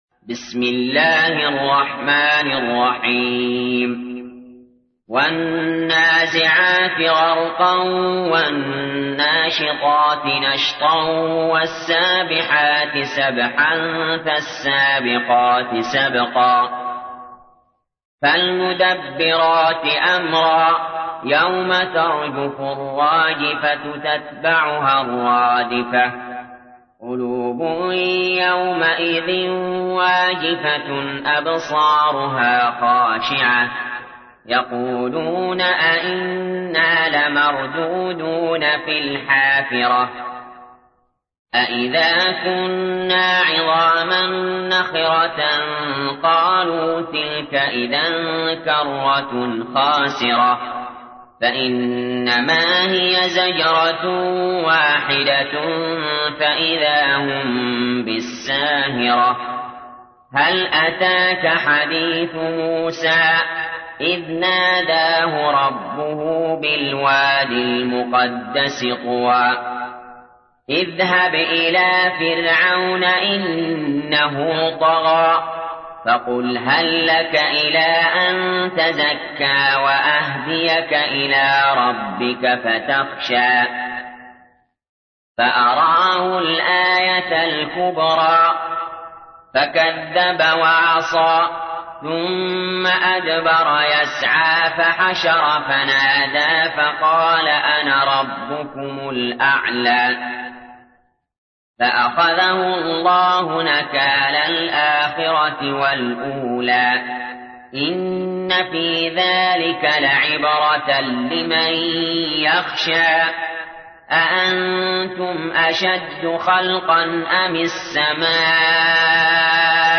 تحميل : 79. سورة النازعات / القارئ علي جابر / القرآن الكريم / موقع يا حسين